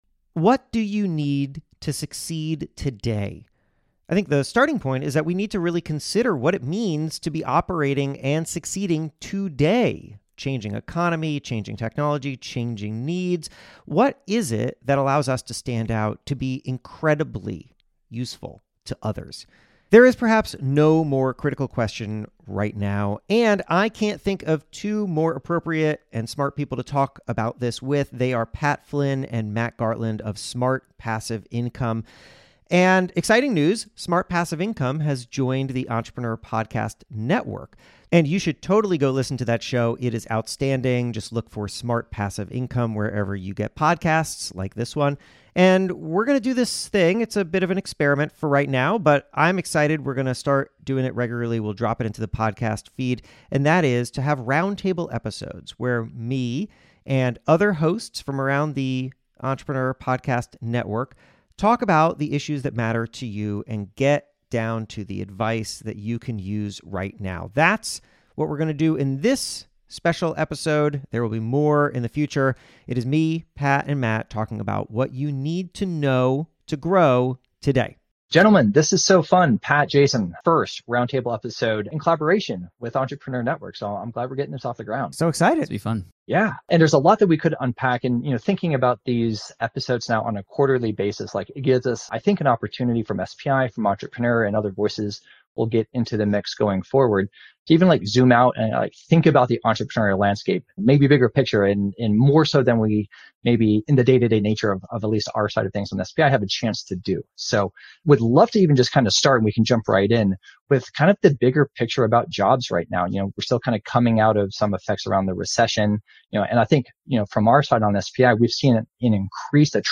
This is a special bonus "roundtable" episode of Problem Solvers, and the beginning of a fun experiment where Entrepreneur Podcast Network hosts join together to discuss the subjects most important to you.